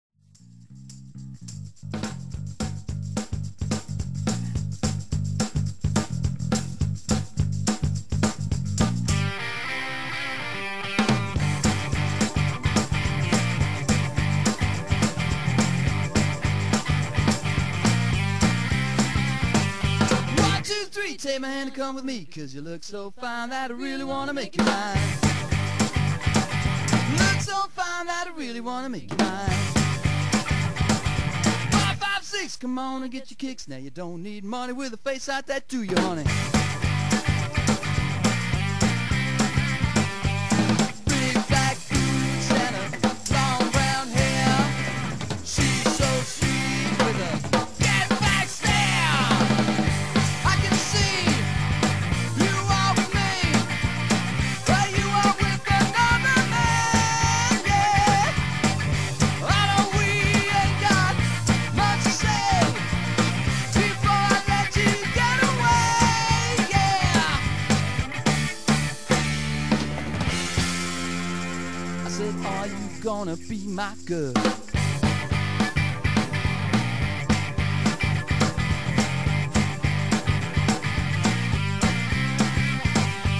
cover bands